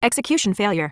OCEFIAudio_en_ExecutionFailure.wav